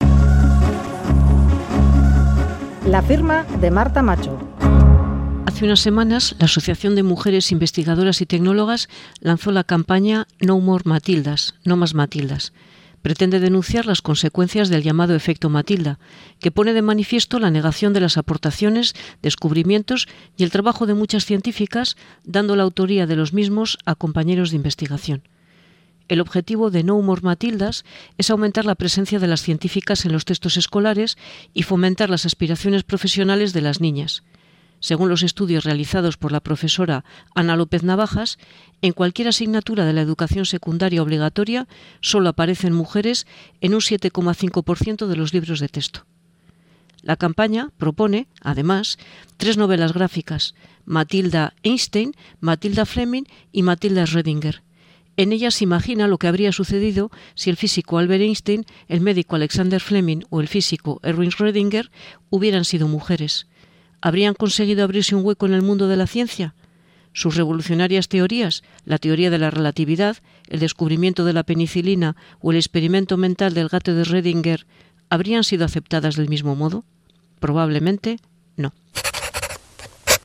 Radio Euskadi LA FIRMA 'No more Matildas' Publicado: 17/02/2021 10:03 (UTC+1) Última actualización: 17/02/2021 10:03 (UTC+1) Columna de opinión en "Boulevard" de Radio Euskadi.